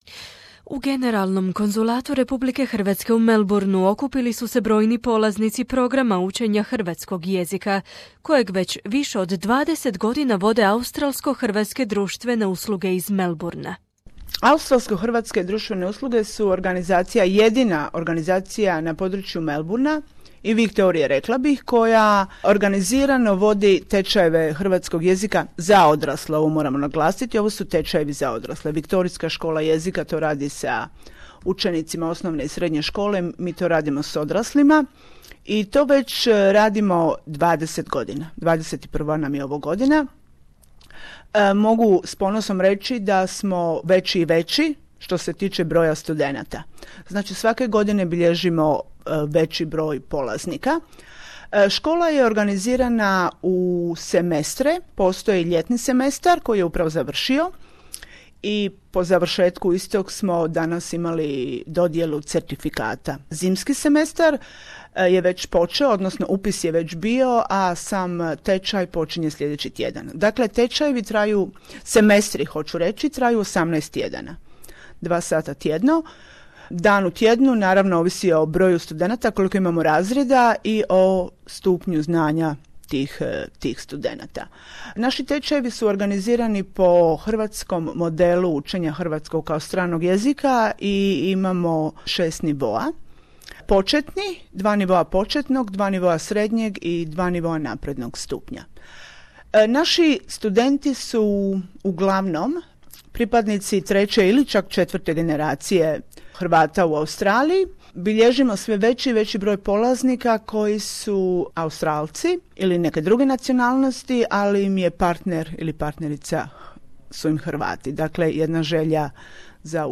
ACCS's Croatian language Classes - Certificate presentation night